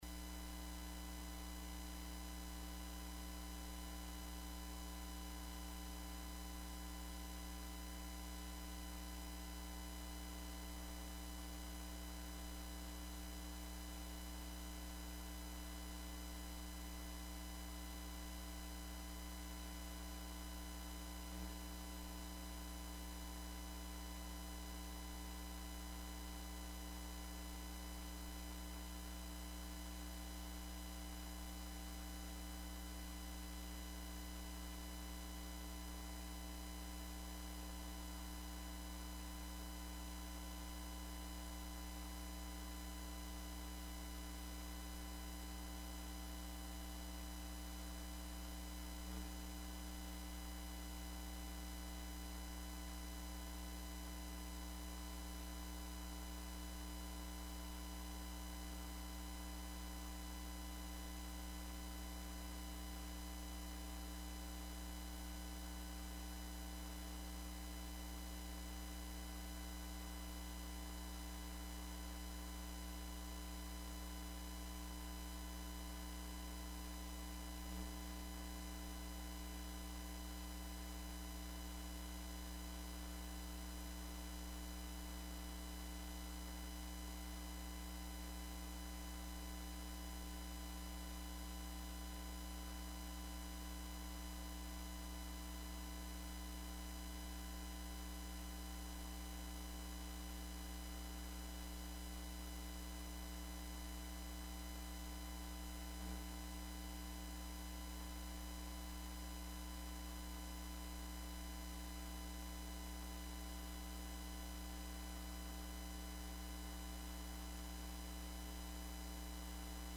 Service Type: 主日崇拜
Topics: 主日證道 « 計算代價 誰是真正的浪子?